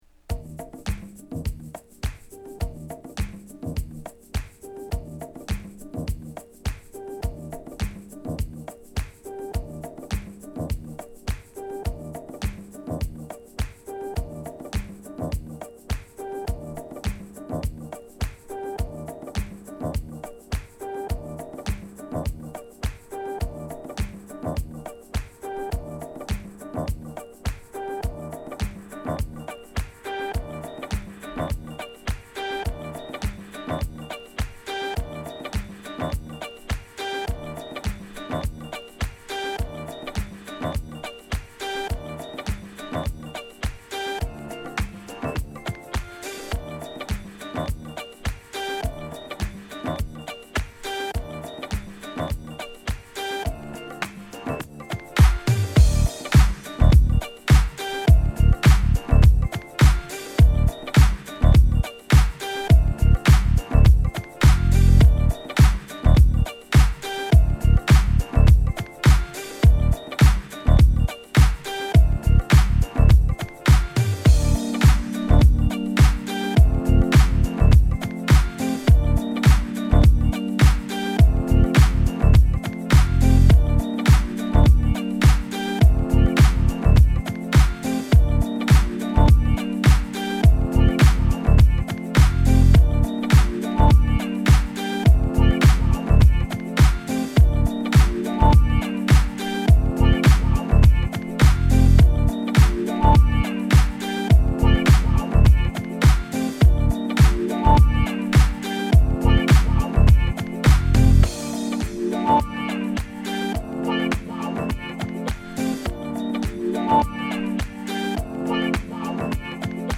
Balearic , Boogie , Disco , Funky , House